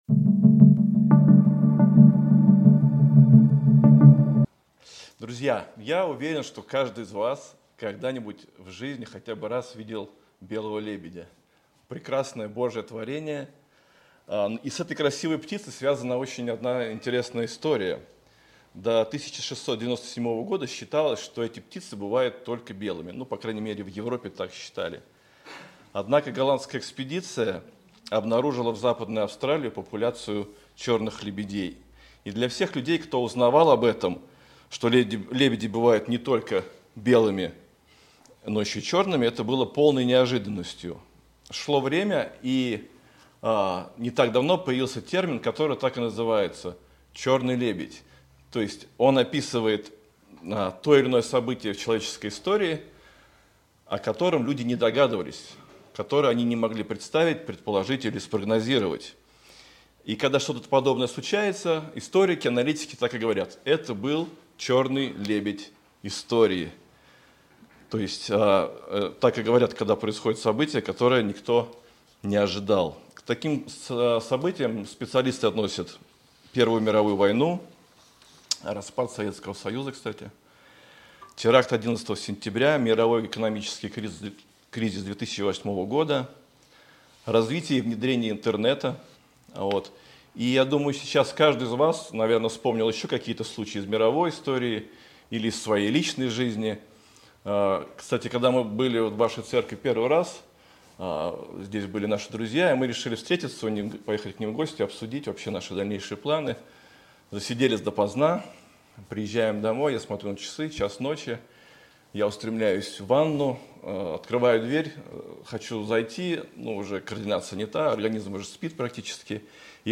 Суверенность Бога в деле спасения Проповедник